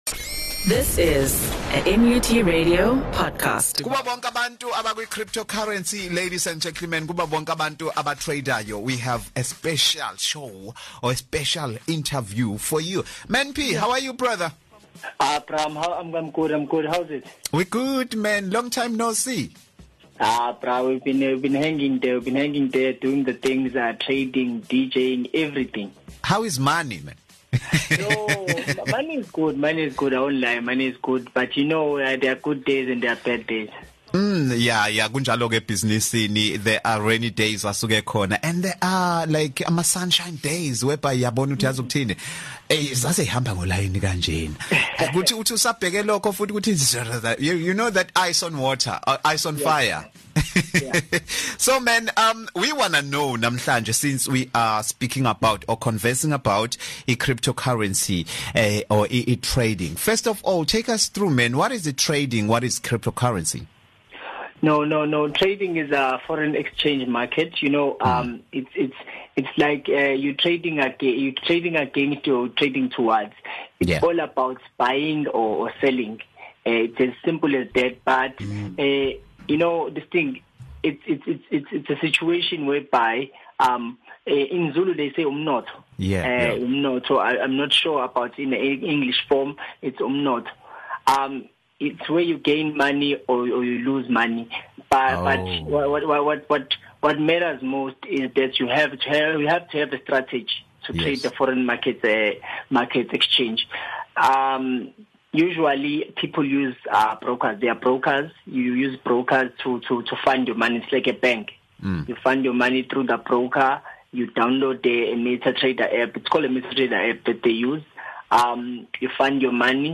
had an interview